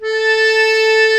A4 ACCORDI-L.wav